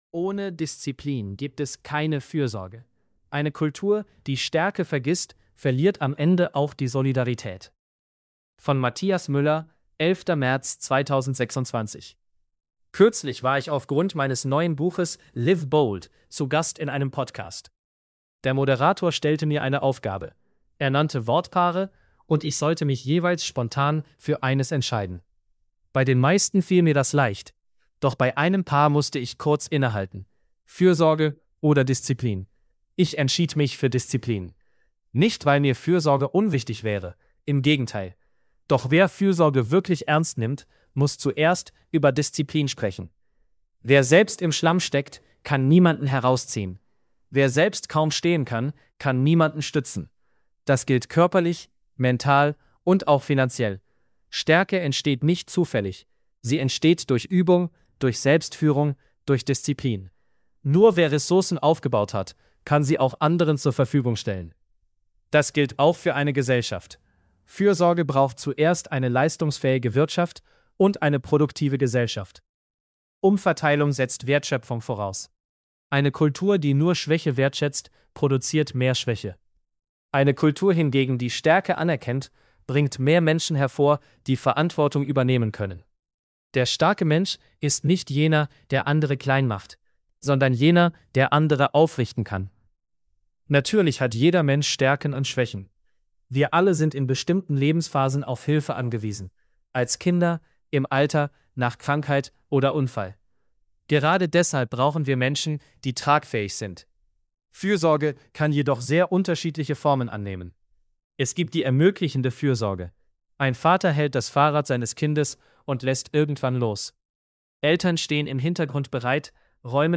Ohne_Disziplin_gibt_es_keine_Frsorge_male.mp3